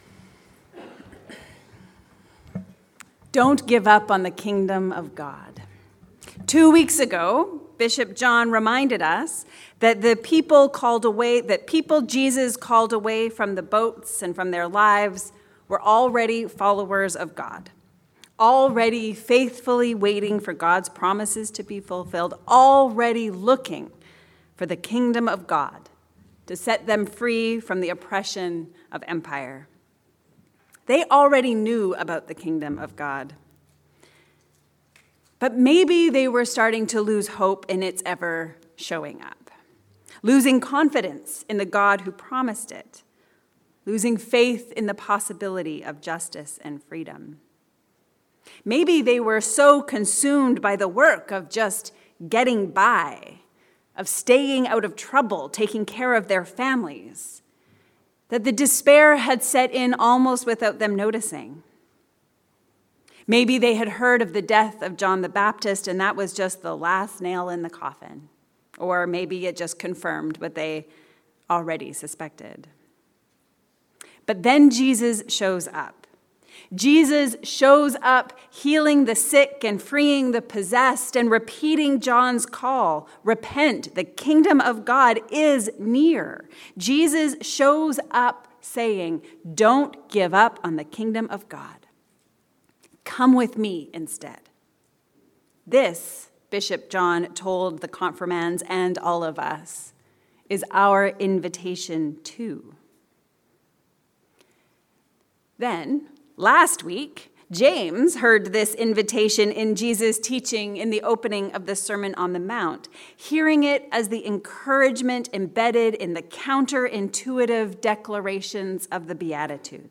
A sermon on Matthew 5:13-20